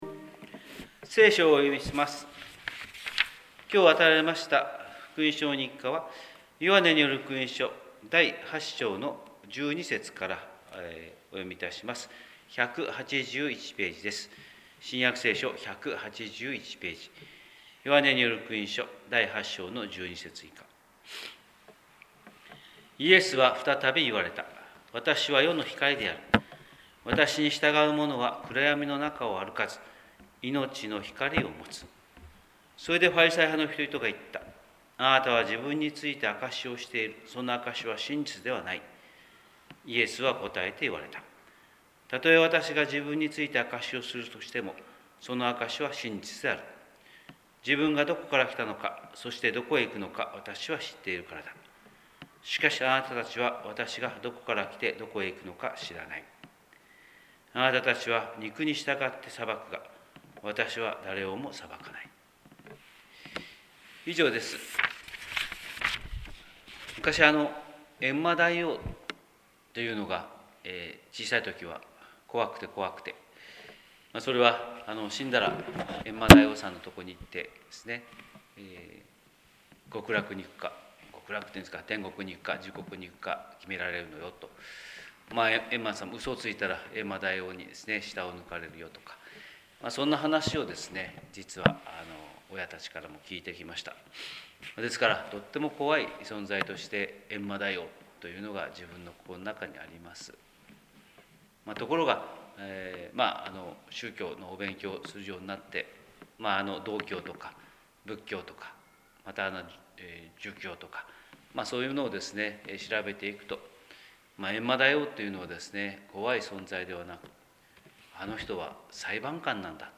神様の色鉛筆（音声説教）: 広島教会朝礼拝241105
広島教会朝礼拝241105「裁くな」